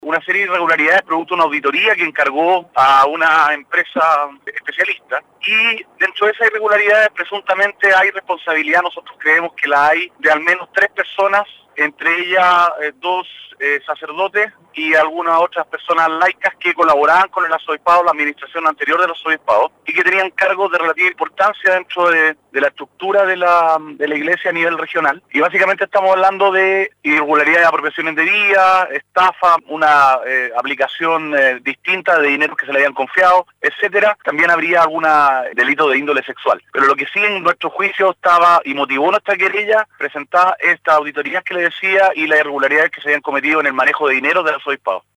Las diligencias del proceso ya están en curso y  la querella fue sometida a trámite por el Tribunal de Garantía, según lo detalló en conversación con Radio Sago, el ex fiscal nacional, Sabas CHahuán,  abogado patrocinante del Arzobispado de Puerto Montt.